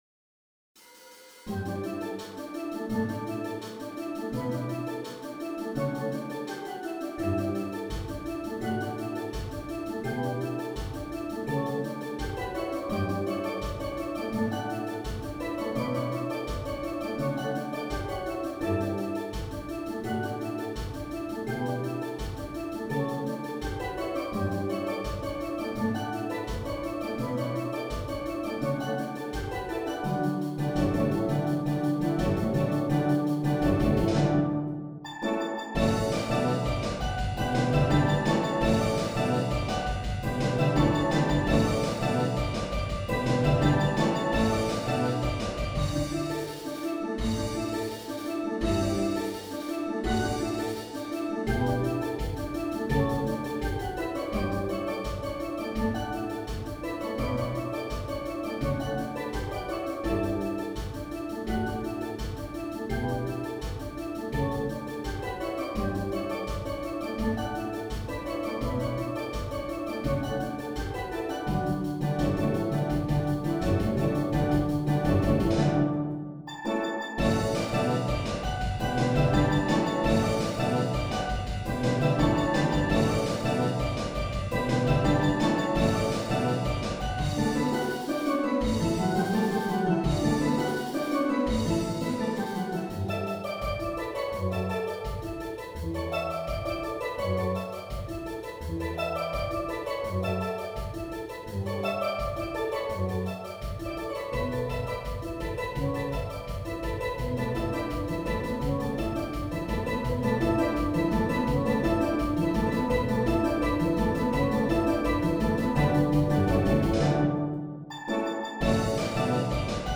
Voicing: Steel Band